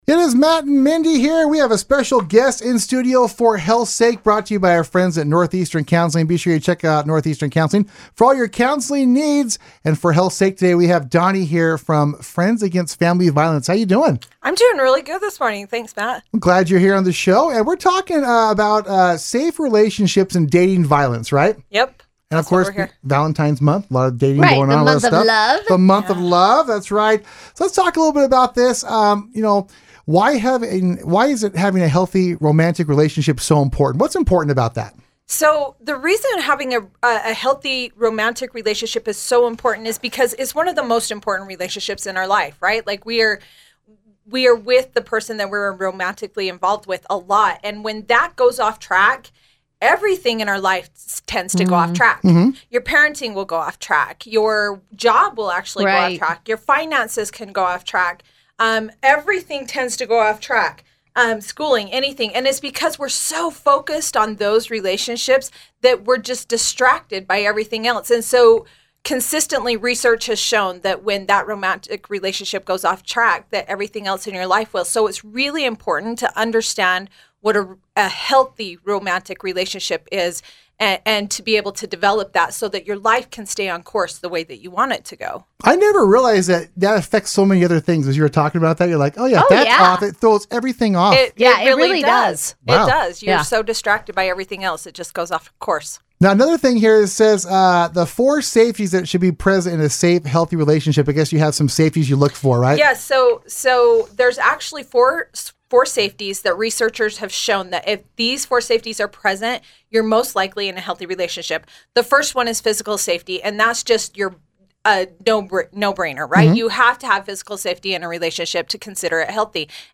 Weekly Radio Spots